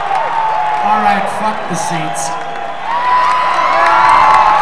06/20/95 - Red Rocks Amphitheater: Morrison, CO [132m]
They get frustrated being seated (Stone declares, "All right, "fuck the seats") and they dump the chairs as they launch into 'Last Exit.'